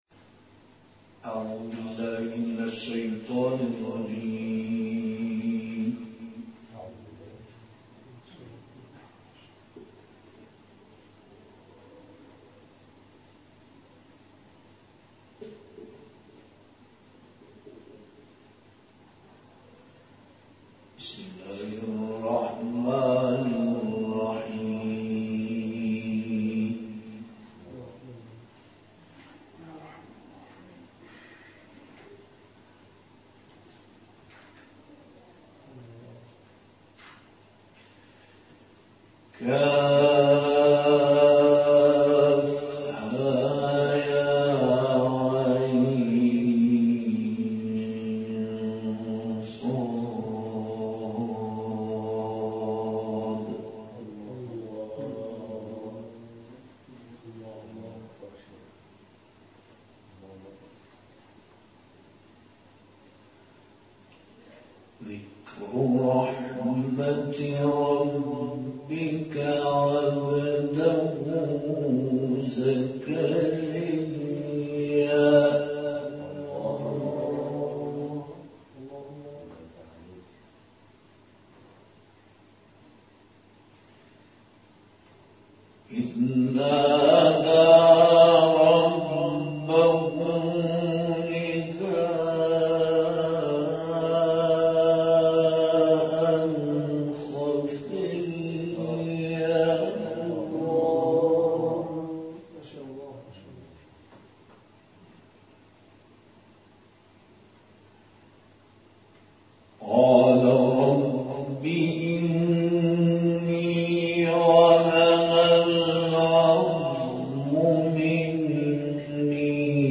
جدیدترین تلاوت
این قاری ممتاز، آیاتی از سوره‌های مریم و حاقه را به مدت 60 دقیقه تلاوت کرد که در ادامه ارائه می‌شود.